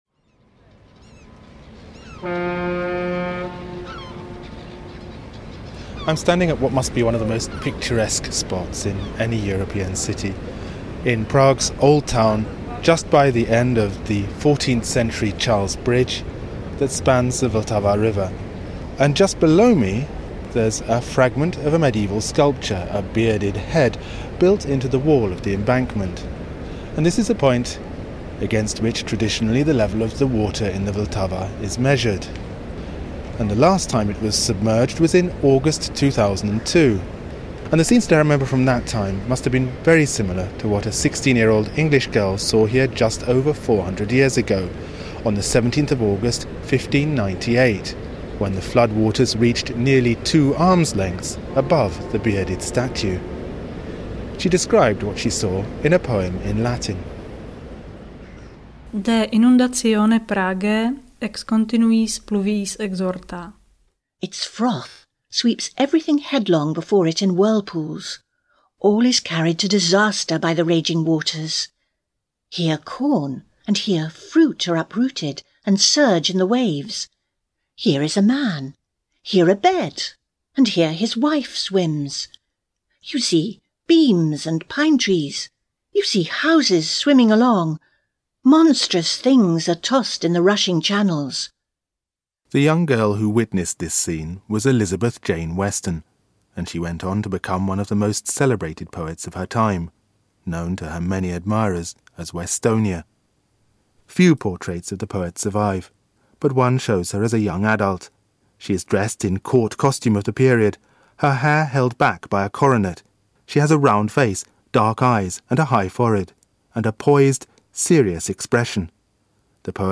I also made a radio documentary about Westonia’s life and work for BBC Radio 3, A Poet in Bohemia .